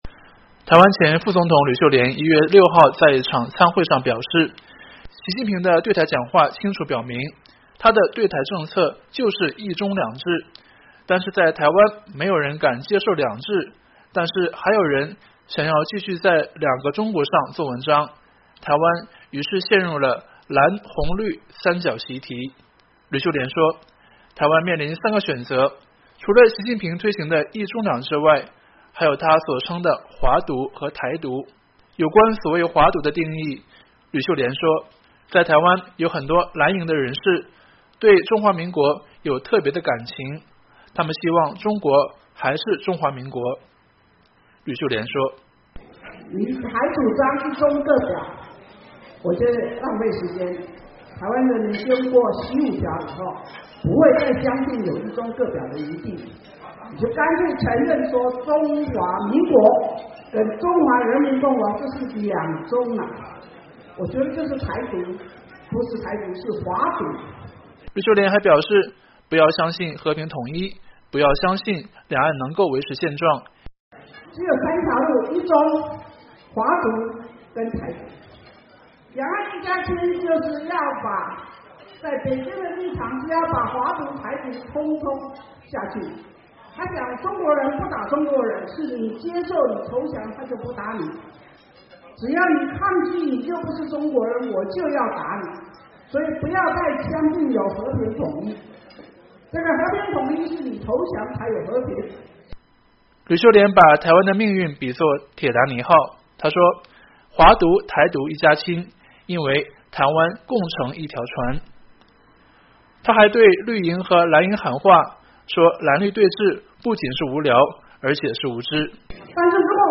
吕秀莲1月6日在一场餐会上表示，“习五条”清楚表明，习近平的对台政策就是“一中两制”，但是在台湾“没有人敢接受‘两制’”，但还有人还想要继续在“两个中国”上做文章，台湾陷入了蓝、红、绿“三角习题”。